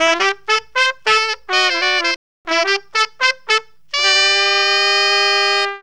HORN RIFF 19.wav